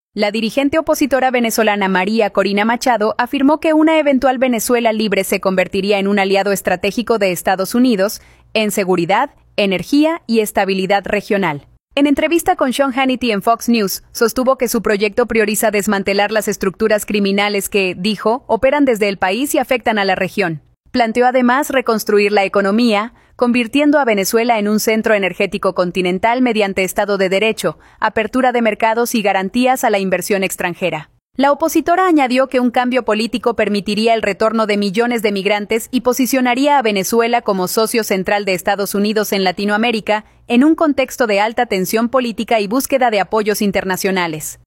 La dirigente opositora venezolana María Corina Machado afirmó que una eventual Venezuela libre se convertiría en un aliado estratégico de Estados Unidos en seguridad, energía y estabilidad regional. En entrevista con Sean Hannity en Fox News, sostuvo que su proyecto prioriza desmantelar las estructuras criminales que, dijo, operan desde el país y afectan a la región.